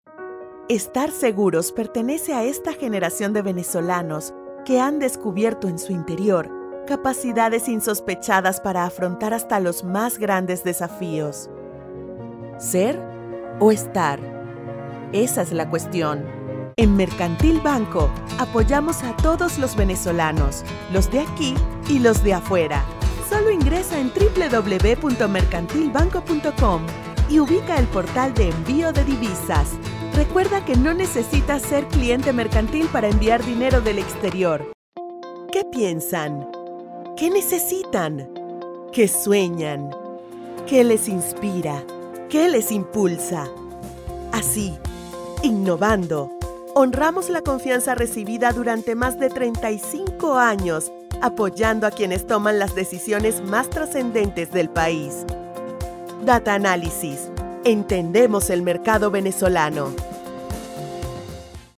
Male 30s , 40s , 50s American English (Native) , Flemish (Native) Approachable , Assured , Authoritative , Bright , Character , Confident , Conversational , Cool , Corporate , Deep , Energetic , Engaging , Friendly , Funny , Gravitas , Natural , Posh , Reassuring , Sarcastic , Smooth , Soft , Upbeat , Versatile , Wacky , Warm , Witty Animation , Character , Commercial , Corporate , Documentary , Educational , E-Learning , Explainer , IVR or Phone Messaging , Narration , Training , Video Game